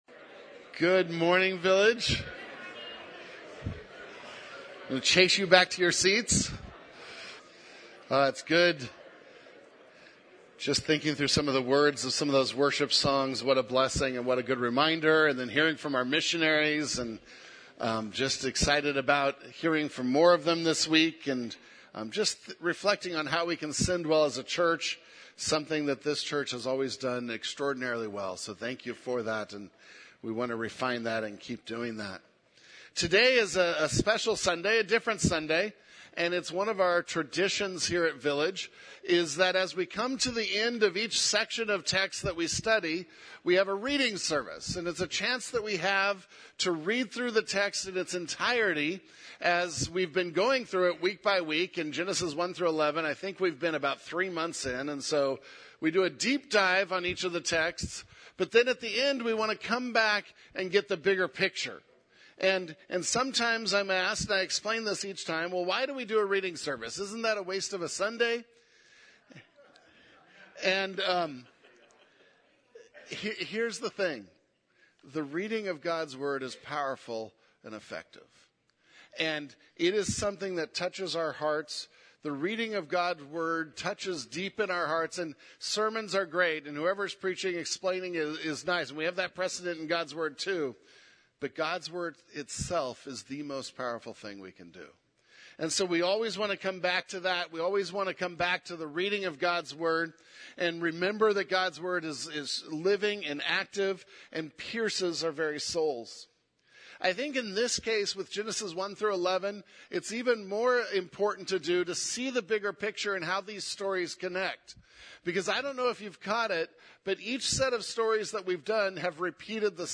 1 Genesis Reading Service (Genesis 1-11) 42:28